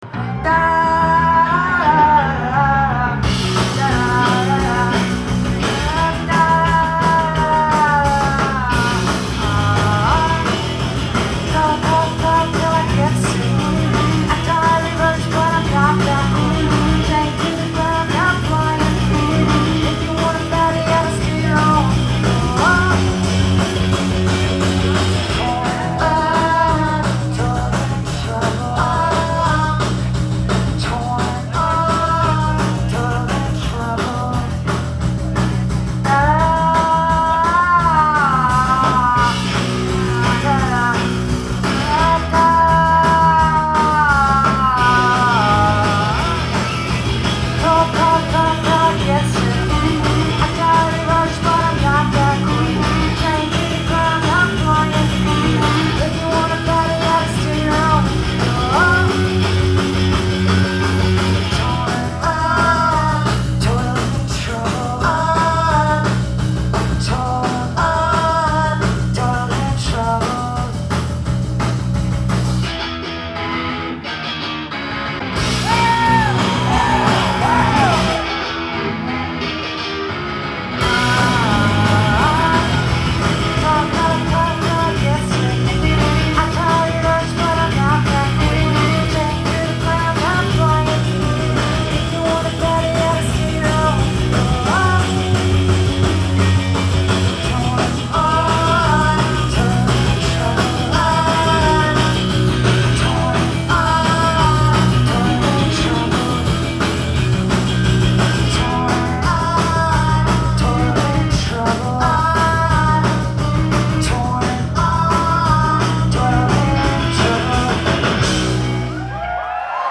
Live at the Paradise
in Boston, MA